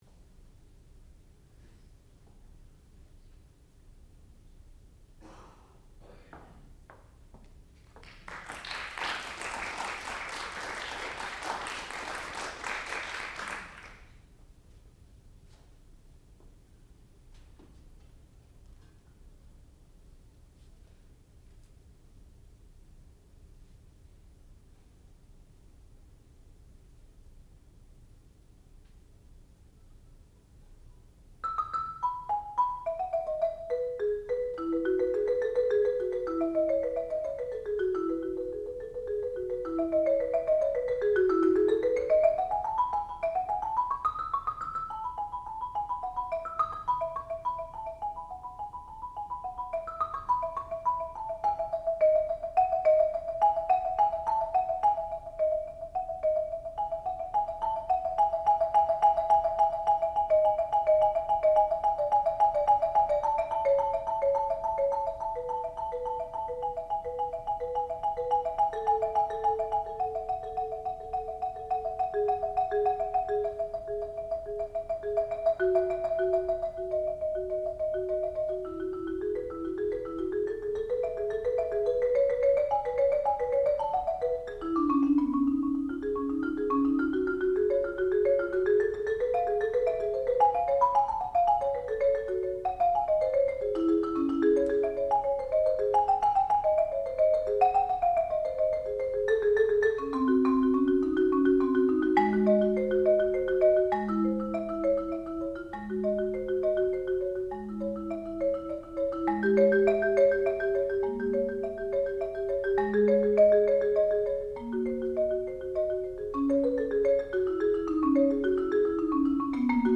sound recording-musical
classical music
Junior Recital
percussion